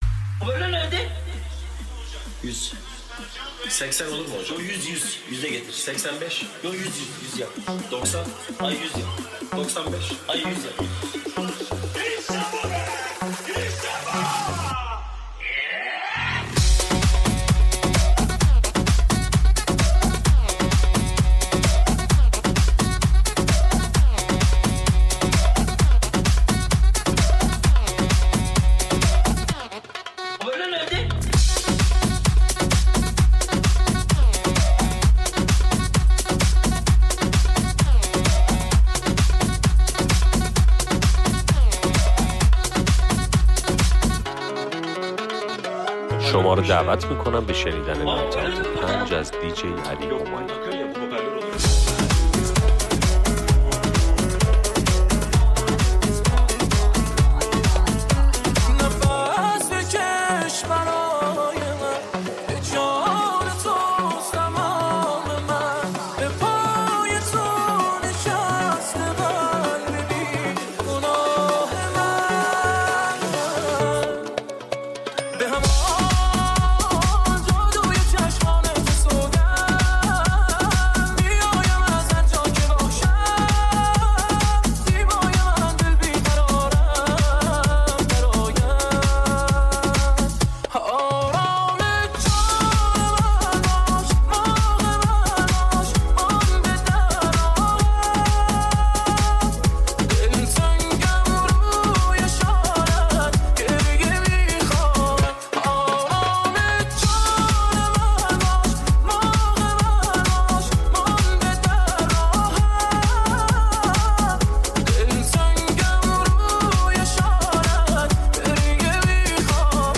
مجموعه‌ای از بهترین ترک‌ها و میکس‌های